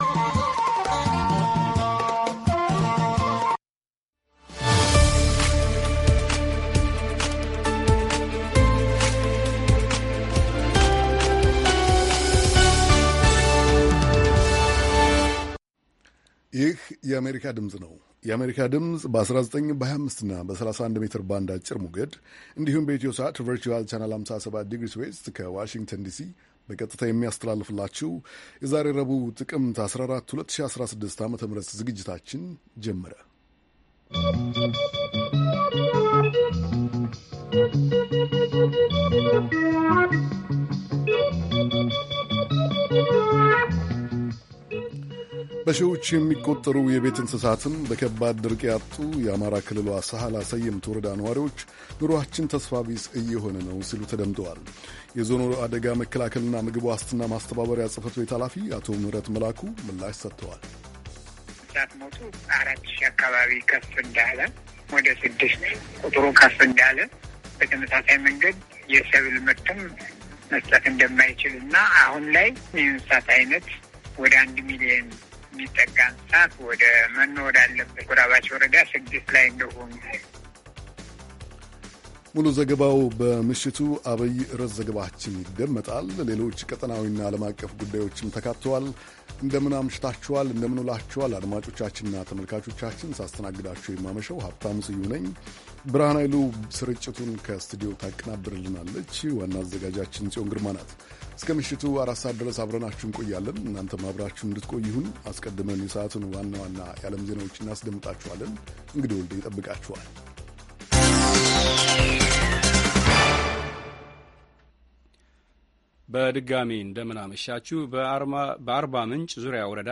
ረቡዕ፡-ከምሽቱ ሦስት ሰዓት የአማርኛ ዜና